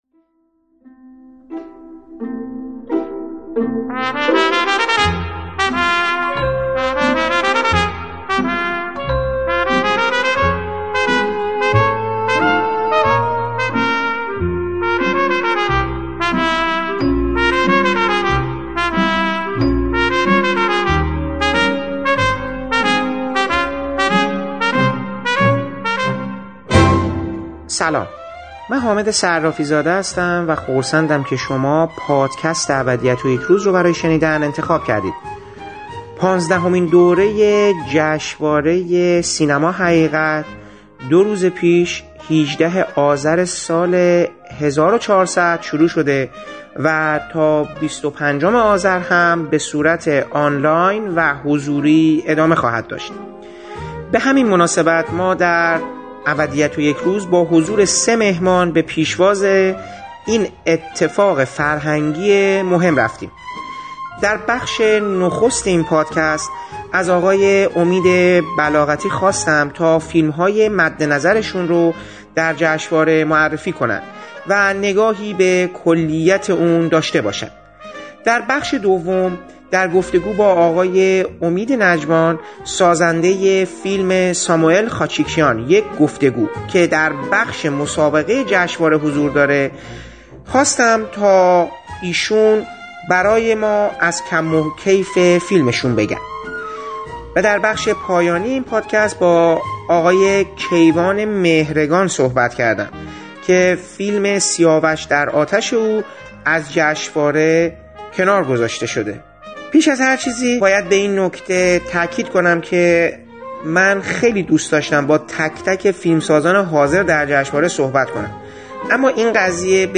پانزدهمین دوره جشنواره فیلم سینما-حقیقت دور روز پیش در تاریخ ۱۸ آذر سال ۱۴۰۰ در تهران آغاز شده و تا ۲۵ آذر بصورت حضوری و آنلاین ادامه خواهد داشت. در این برنامه ابدیت و یک روز با سه گفت‌وگو به پیشواز این رخداد مهم فرهنگی هنری رفته‌ایم.